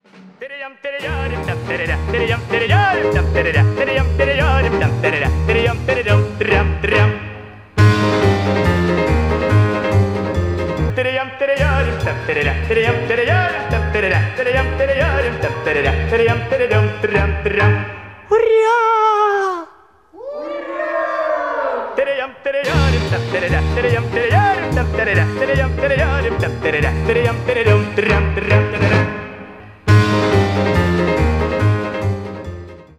Рингтон Весёлая песенка на звонок